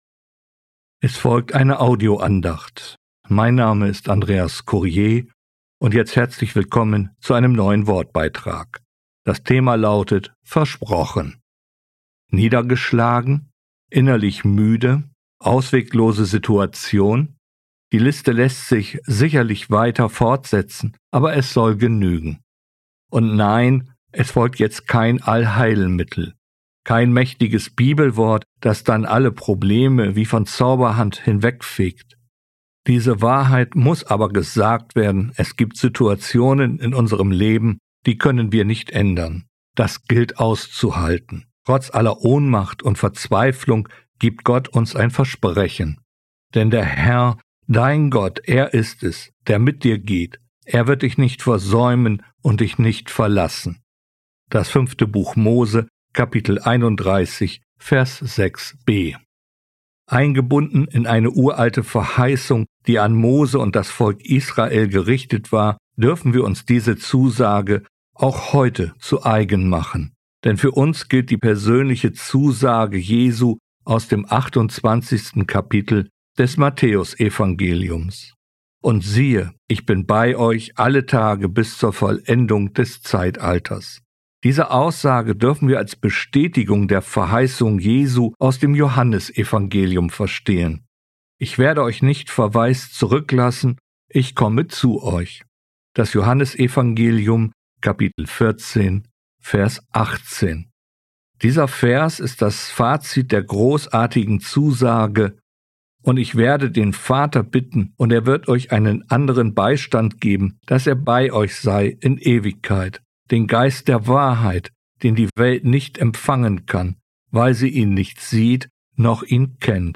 Versprochen, eine Audio-Andacht